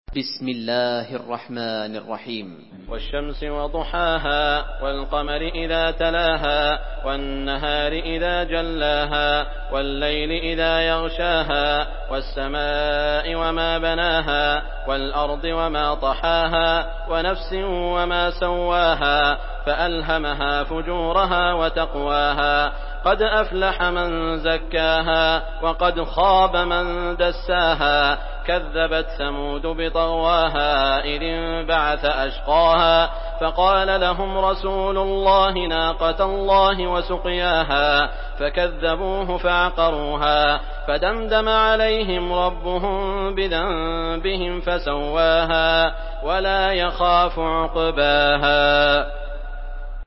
Surah Şems MP3 by Saud Al Shuraim in Hafs An Asim narration.
Murattal